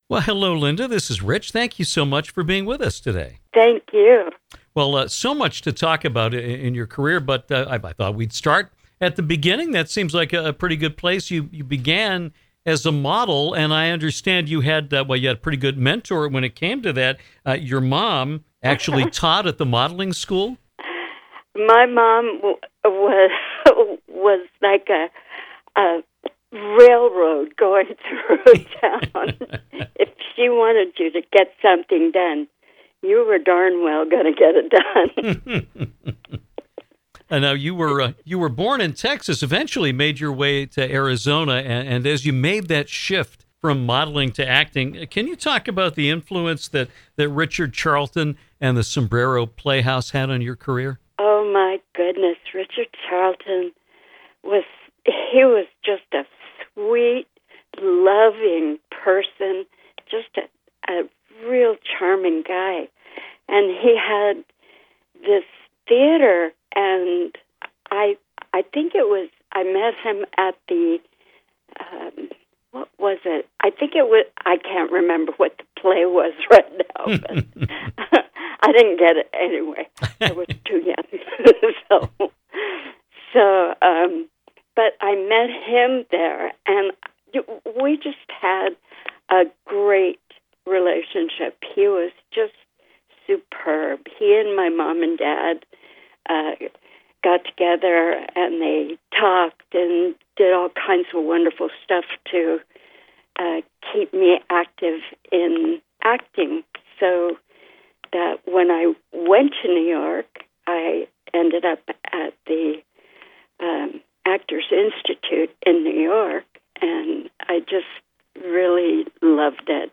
Great interview.